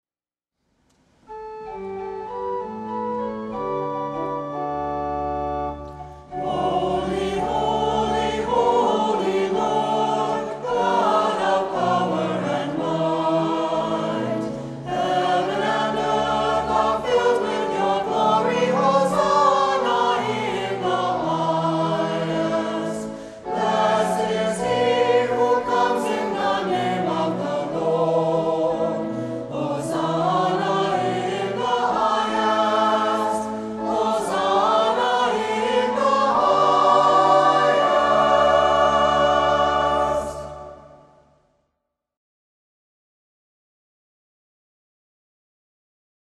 Music for Worship
SATB, Organ (Piano)